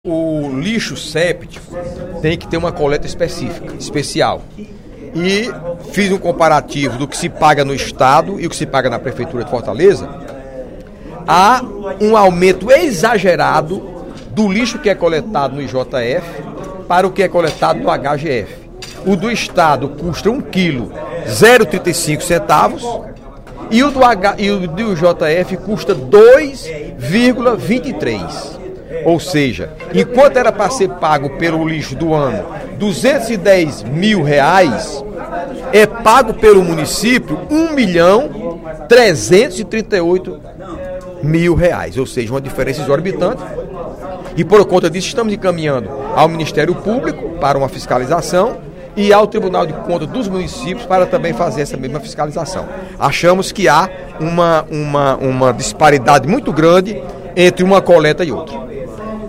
O deputado Heitor Férrer (PSB) informou, durante o primeiro expediente da sessão plenária desta quarta-feira (17/02), que irá protocolar uma representação junto ao Tribunal de Contas do Município e o Ministério Público para que seja avaliado o valor pago pela coleta do lixo séptico (hospitalar) pelo município de Fortaleza.
Dep. Heitor Férrer (PSB) Agência de Notícias da ALCE